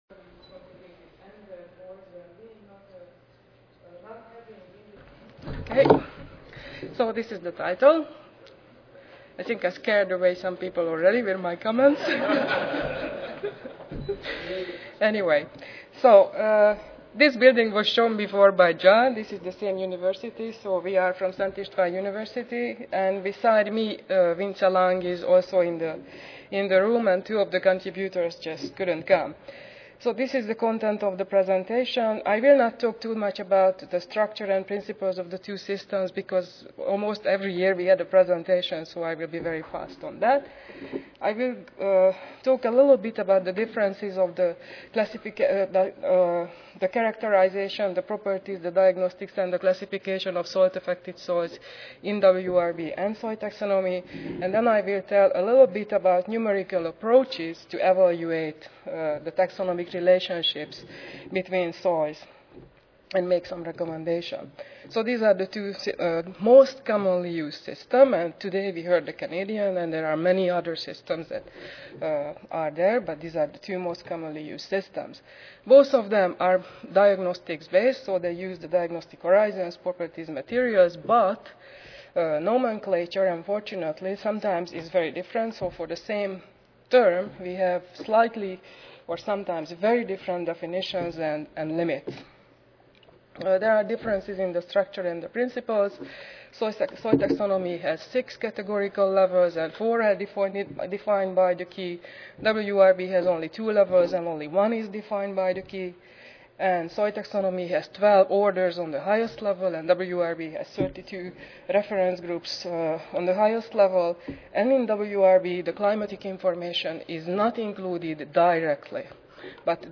Szent Istvan University Recorded Presentation Audio File